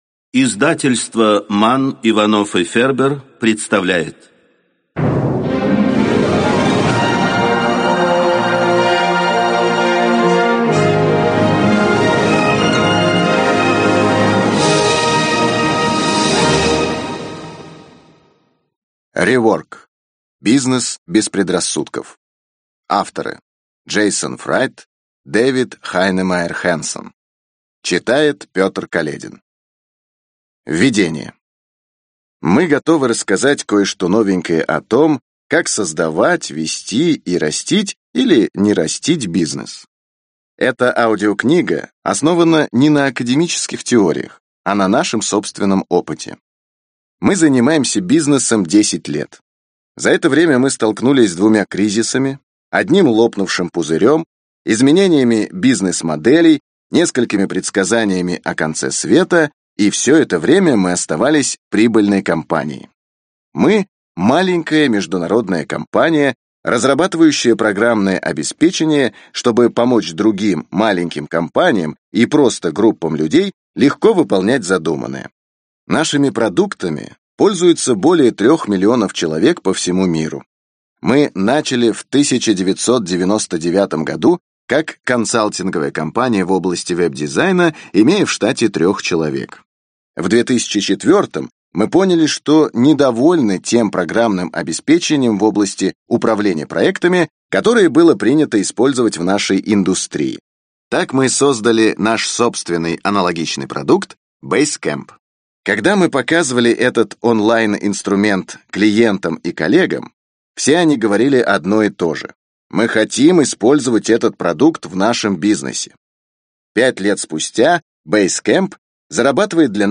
Аудиокнига Rework. Бизнес без предрассудков | Библиотека аудиокниг